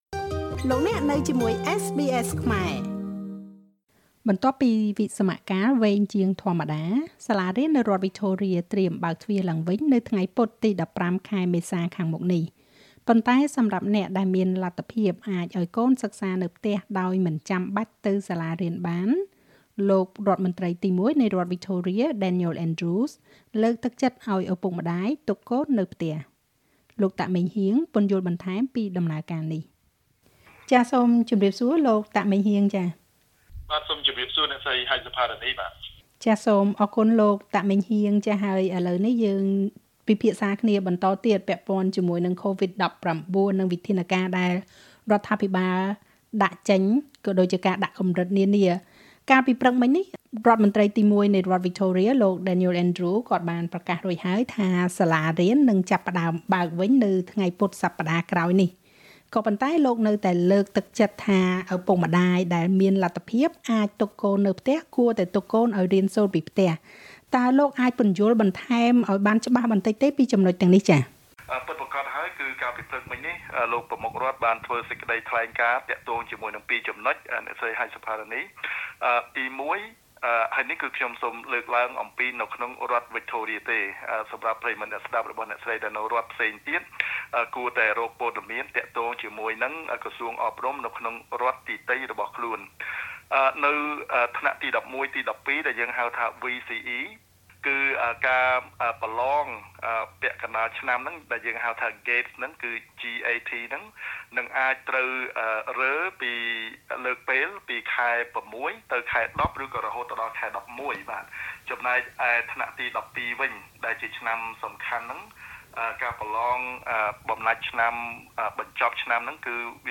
បន្ទាប់ពីវិស្សមកាលវែងជាងធម្មតា សាលារៀននៅរដ្ឋវិចថូរៀ កំណត់ឲ្យបើកទ្វារឡើងវិញនៅថ្ងៃពុធទី 15 ខែមេសា ខាងមុខនេះ ប៉ុន្តែសម្រាប់អ្នកដែលមានលទ្ធភាពអាចឲ្យកូនសិក្សានៅផ្ទះដោយមិនចាំបាច់ទៅសាលារៀនបាន លោកប្រមុខរដ្ឋ Daniel Andrews លើកទឹកចិត្តឲ្យឳពុកម្តាយទុកកូននៅផ្ទះ។ លោក តាក ម៉េងហ៊ាង សមាជិកសភានៃរដ្ឋវិចថូរៀពន្យល់បន្ថែម។